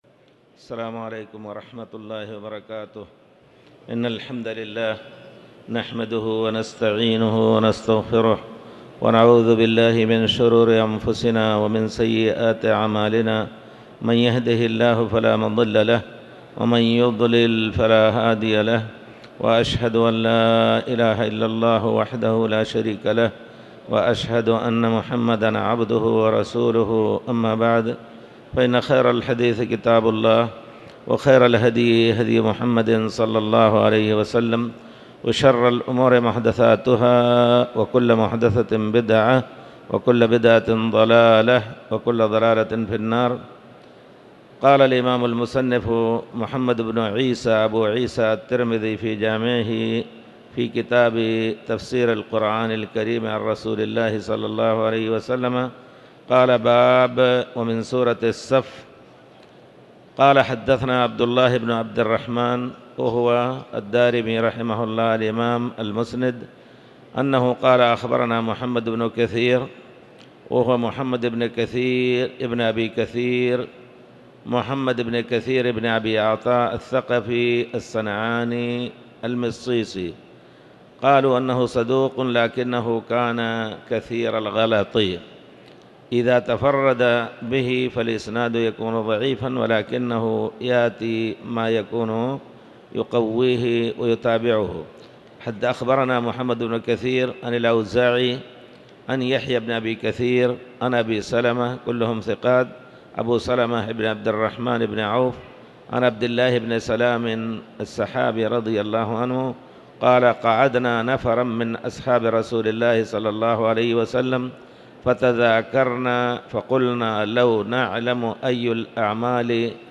تاريخ النشر ٢٩ ربيع الثاني ١٤٤٠ هـ المكان: المسجد الحرام الشيخ